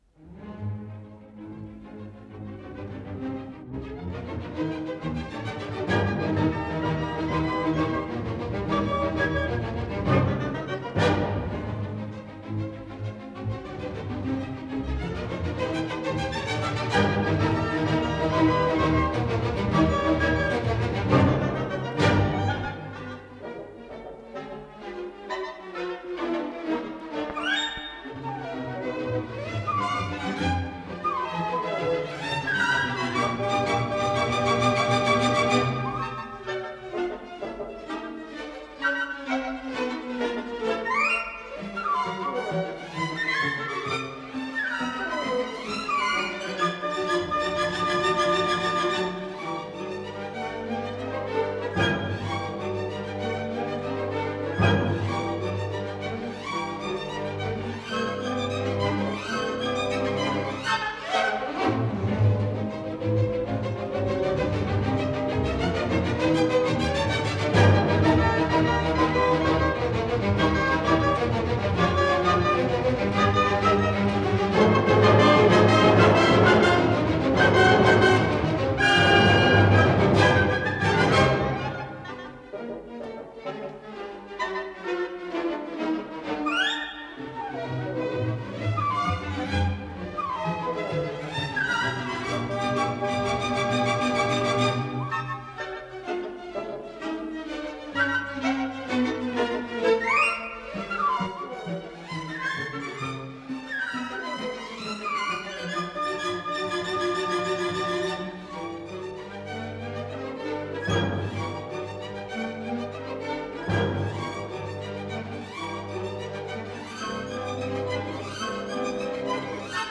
ПКС-07520 — Симфония №2 — Ретро-архив Аудио
Исполнитель: Государственный симфонический оркестр СССР